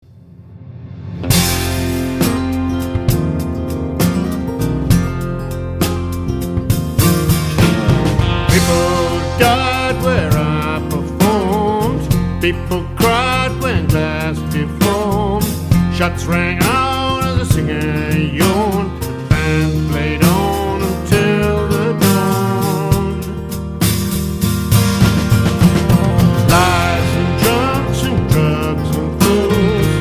is also another simple song with great clear melodic guitar.